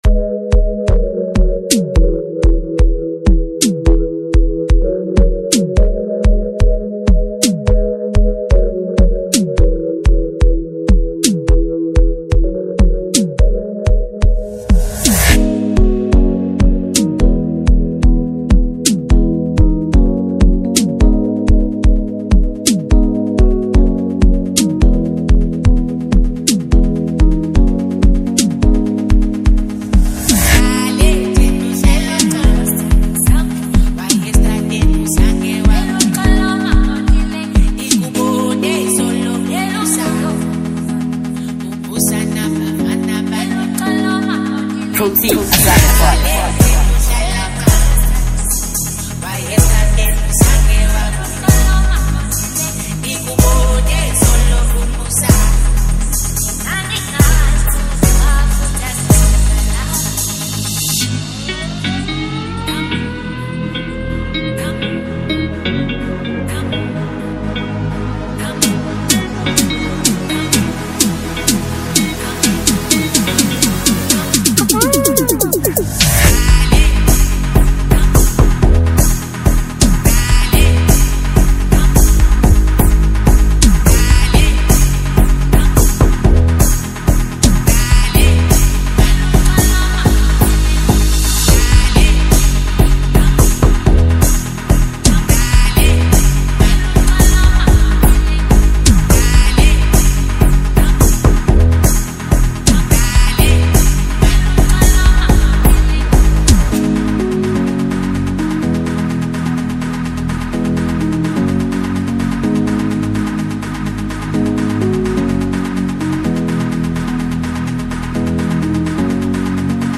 Home » Gqom